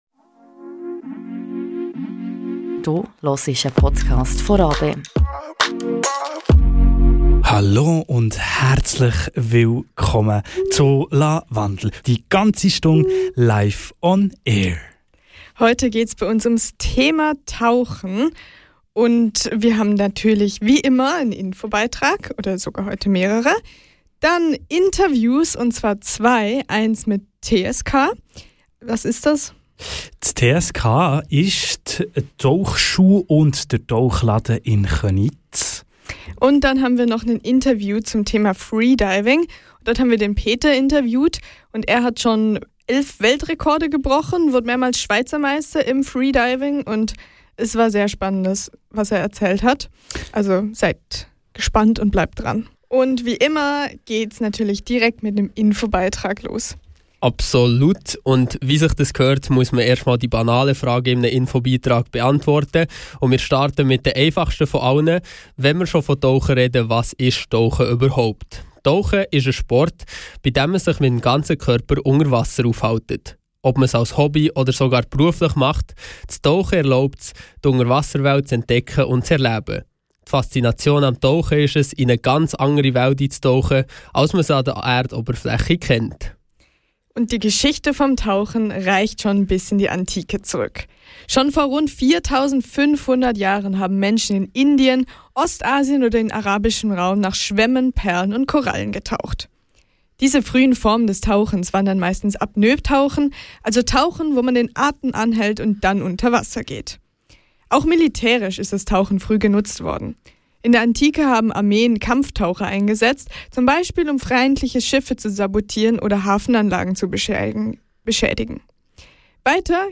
Stille, unbekannte Welten und exotische Tierarten - bei einem Tauchgang kann man das erleben. In dieser Folge gehen wir dieser faszinierenden Sportart mit Interviews und Infobeiträgen auf den Grund.